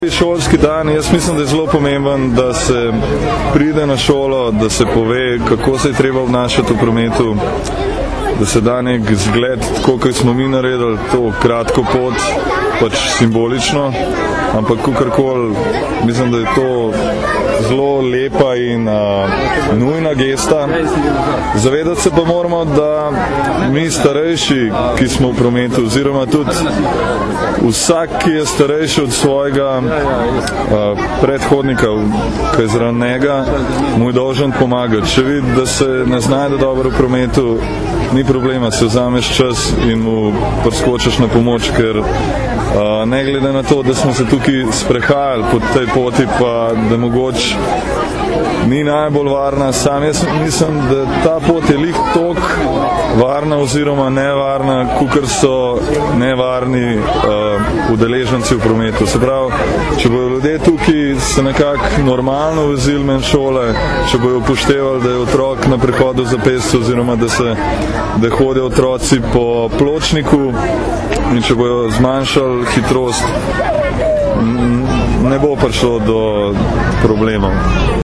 Zvočni posnetek izjave vrhunskega športnika, zaposlenega v policiji, Luke Špika (mp3)